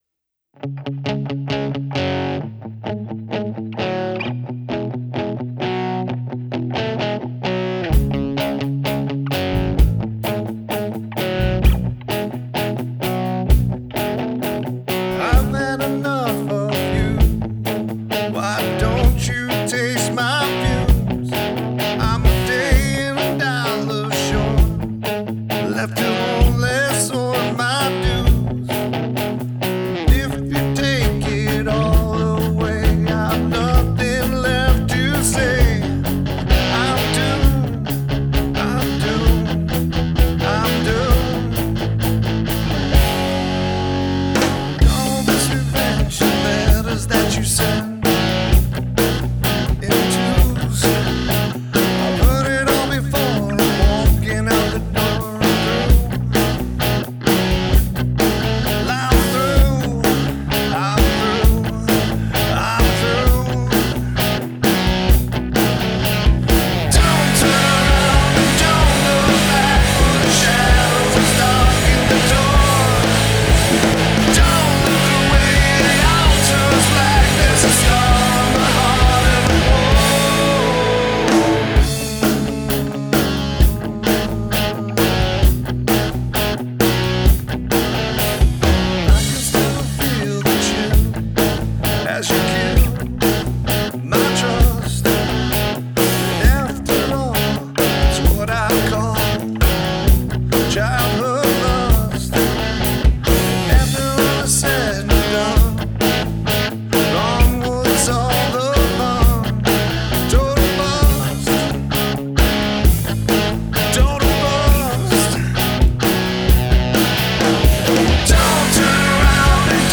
Guitar/Vocals
Lead Vocals
Drums
Bass
Keys